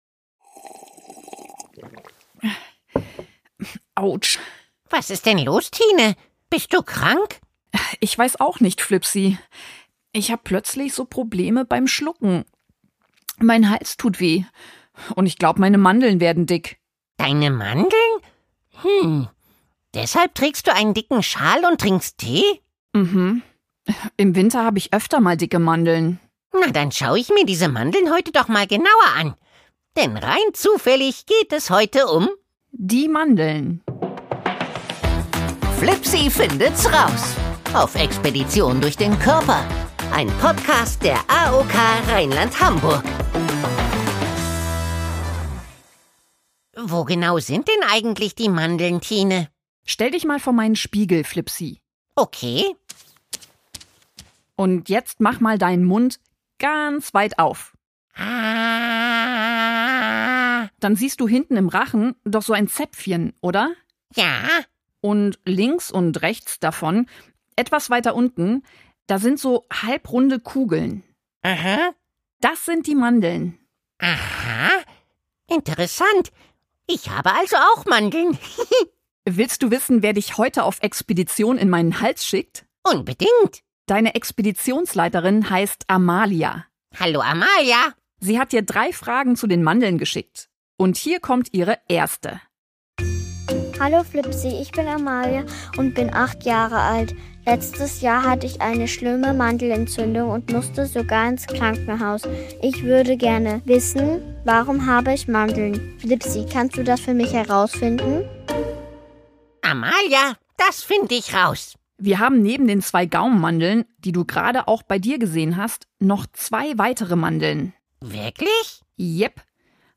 Und gequizzt wird natürlich auch!